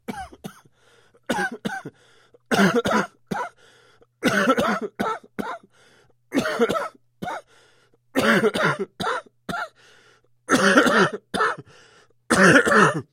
Звук кашля при бронхите у мужчин: основные симптомы